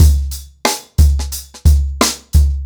TheStakeHouse-90BPM.35.wav